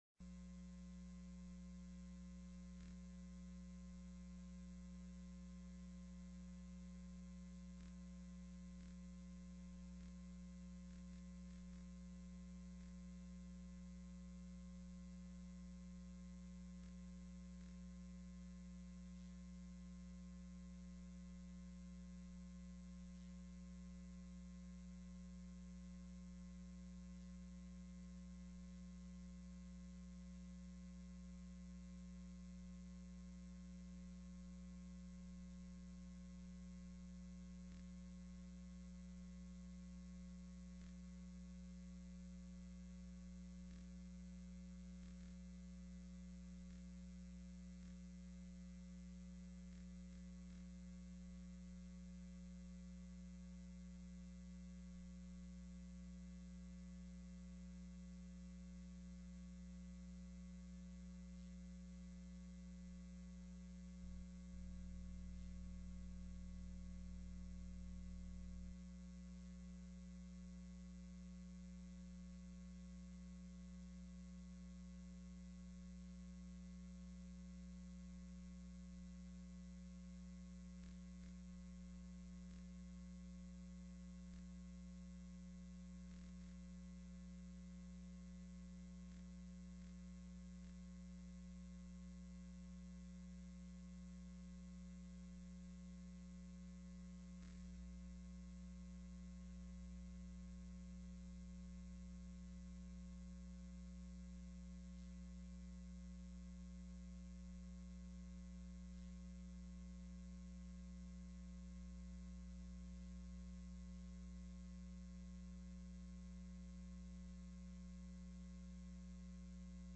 The audio recordings are captured by our records offices as the official record of the meeting and will have more accurate timestamps.
2:47:52 PM REPRESENTATIVE SCOTT KAWASAKI, SPONSOR, introduced the bill.